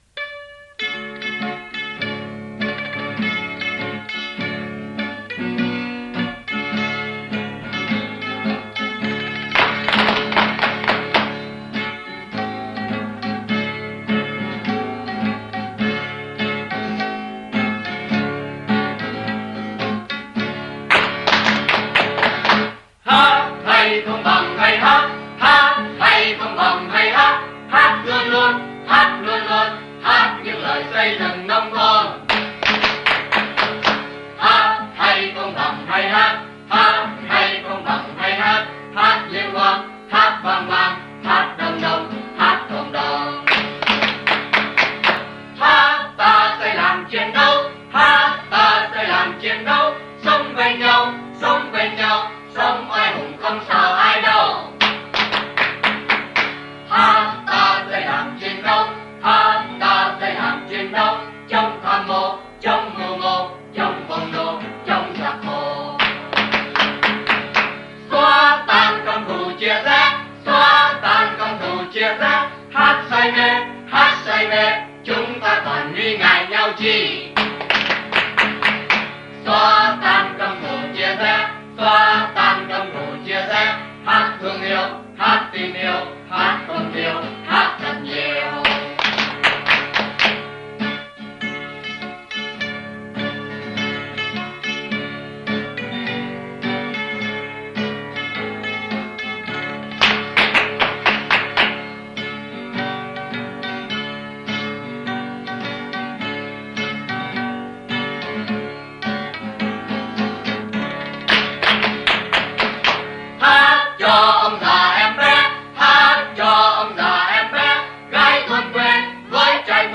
Nhạc vui sinh hoạt XDNT
hợp xướng